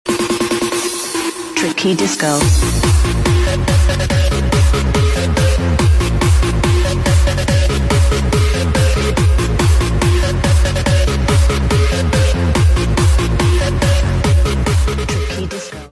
*Sound driver* Dublin bus | sound effects free download
L25 to dun laoghaire stn